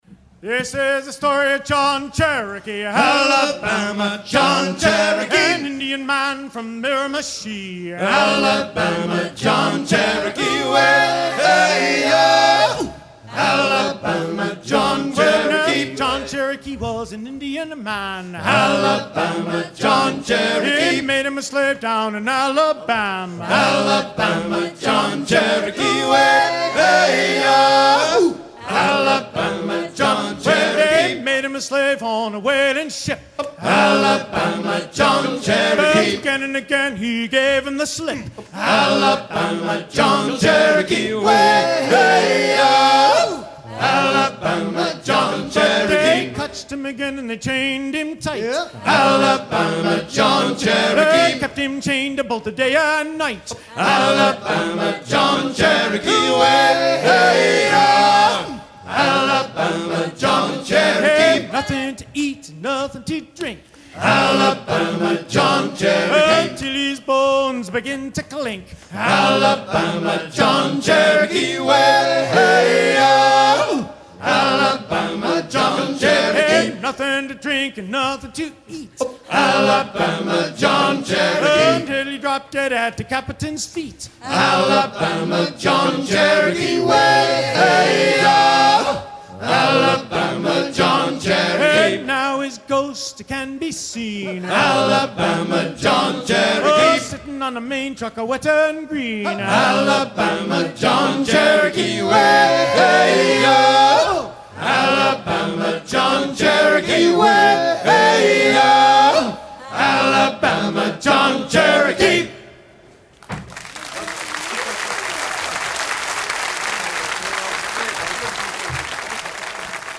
chantey
gestuel : à hisser main sur main
Pièce musicale éditée